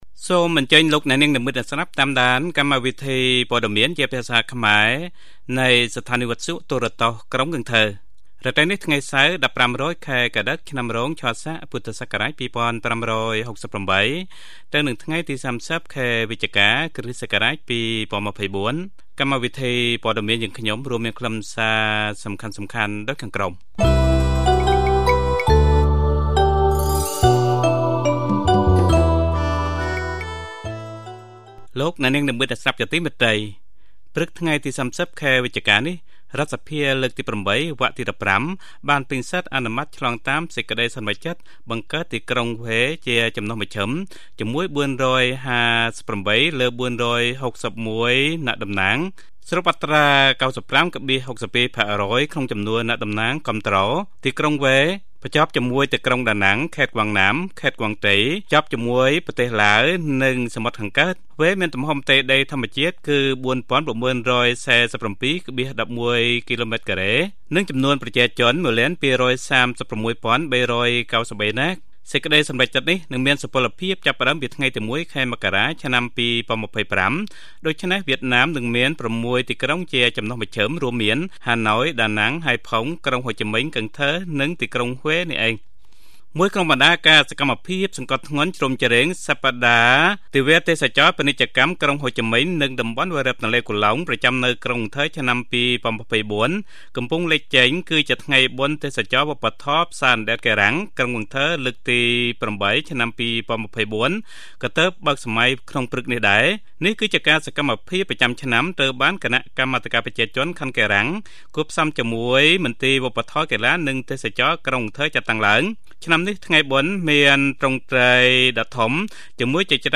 Bản tin tiếng Khmer tối 30/12/2024